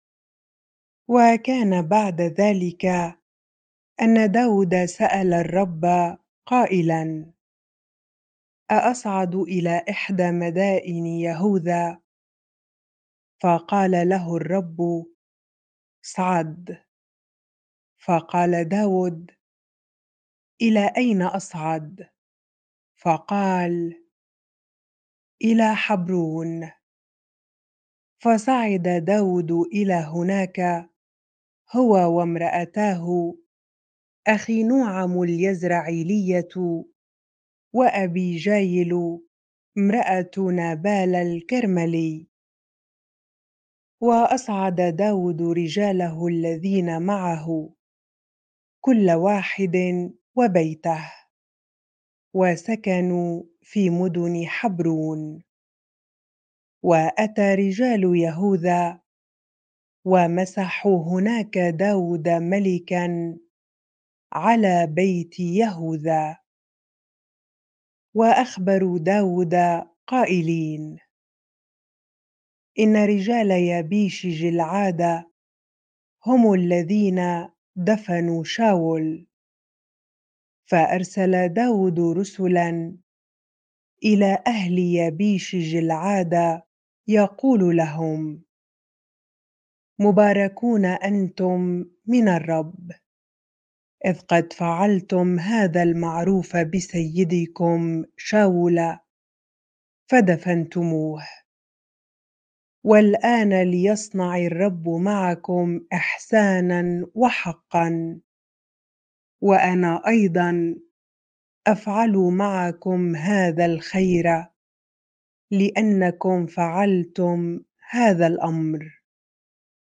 bible-reading-2Samuel 2 ar